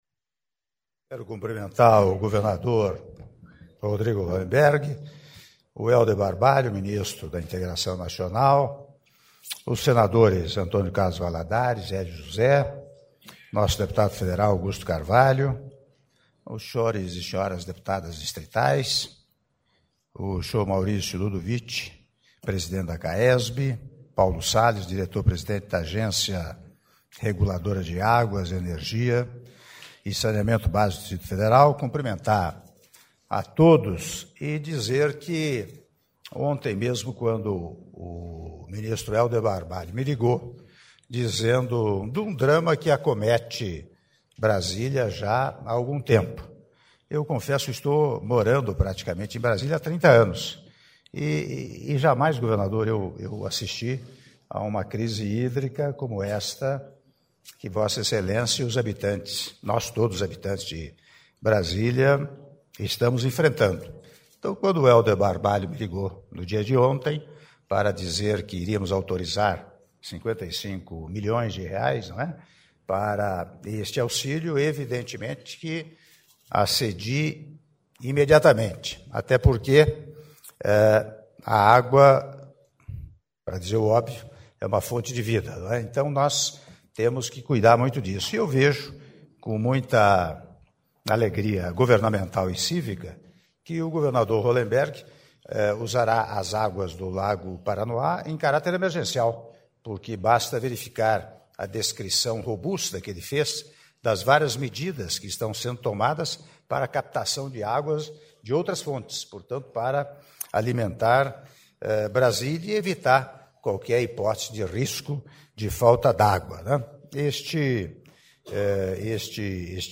Áudio do discurso do Presidente da República, Michel Temer, durante cerimônia de Autorização do Processo Licitatório para Implantação do Sistema de Captação de Água do Lago Paranoá - Brasília/DF (05min27s)